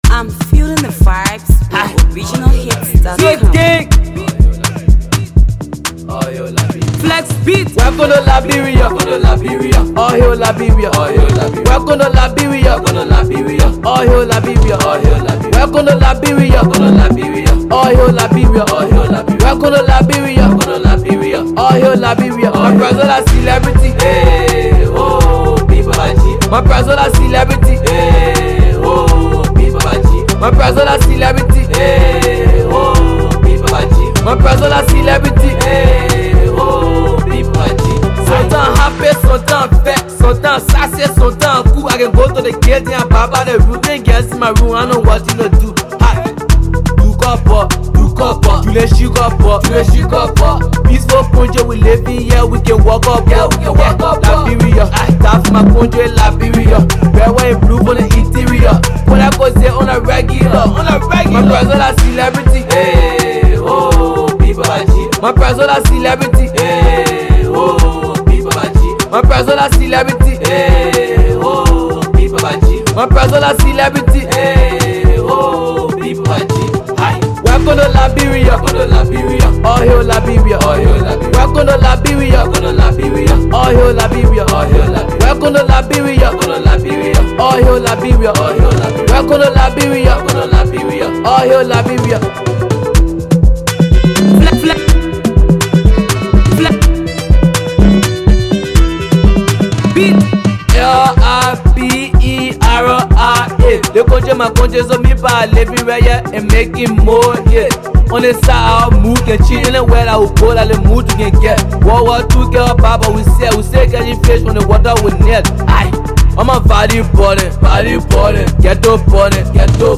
Xtreets jam